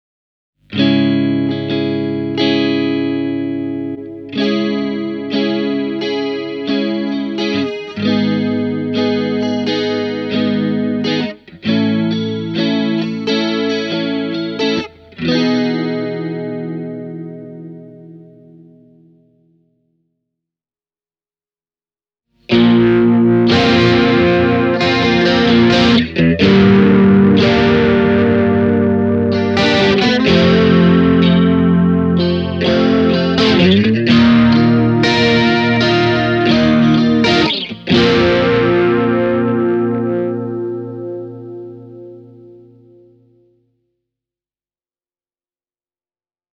Ensemble King (59 €) on Mooer-pikkuspedujen helppokäyttöinen chorus-pedaali.
Pikku-Mooerista lähtee kauniisti tiheä, klassinen chorussoundi, joka sopii mainiosti joka lähtöön. Ensemble Kingin oma kohina on kiitettävän alhainen: